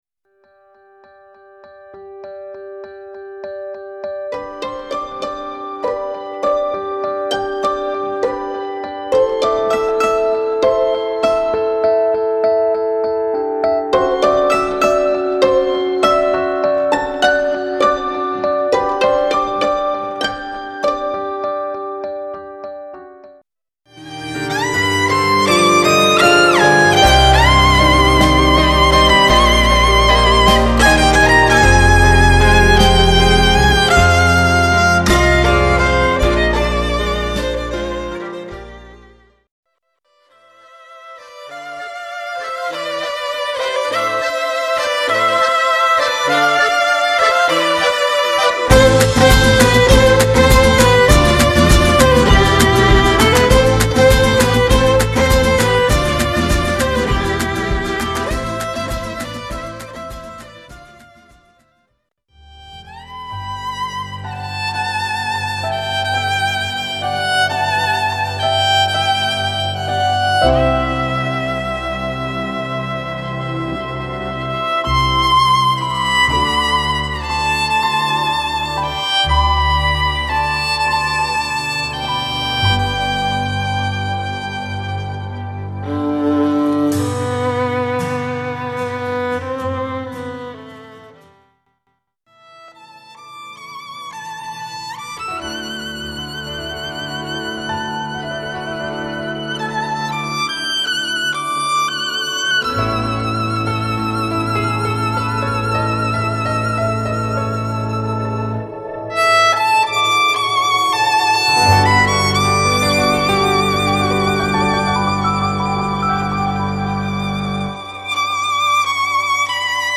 housle, zp�v
piano
kytary, basa, kl�vesy
akordeon